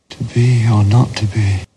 But when English speakers quote this line, we practically always deaccent the repeated to be, putting the main accent on the new word, not: to be or not to be. This normal pattern is the one used by the actor David Tennant: